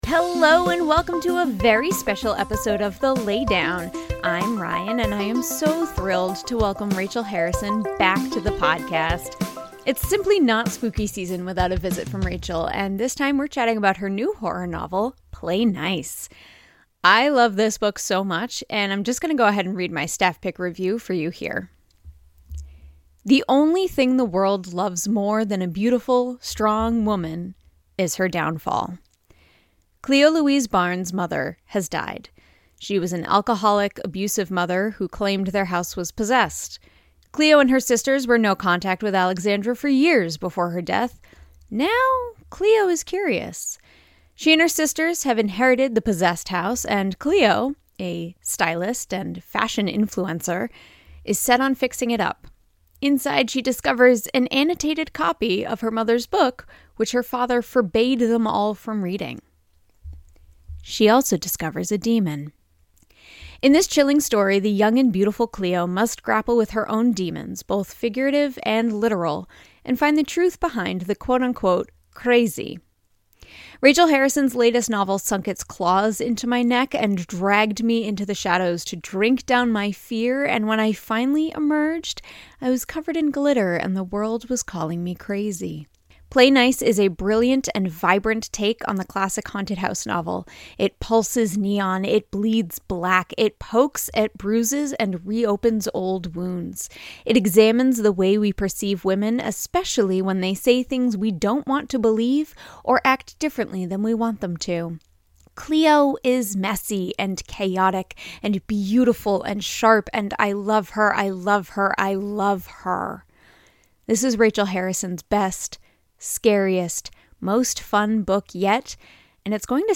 Rachel Harrison Interview for Play Nice!